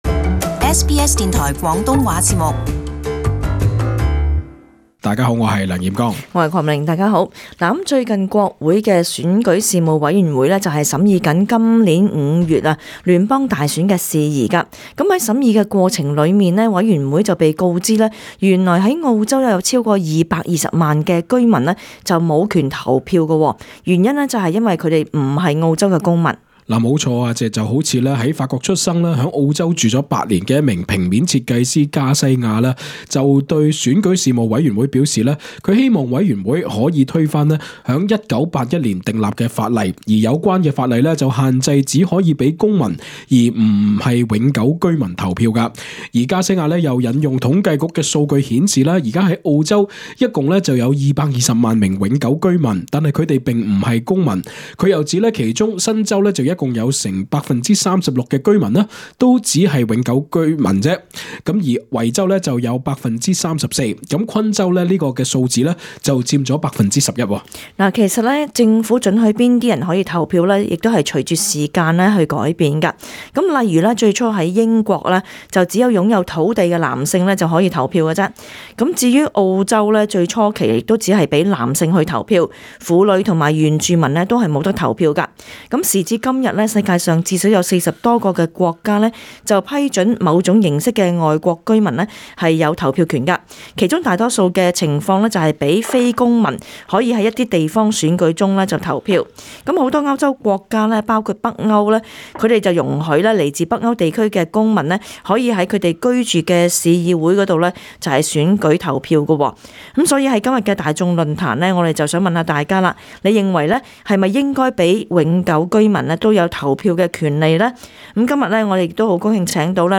與聽眾一起討論有關話題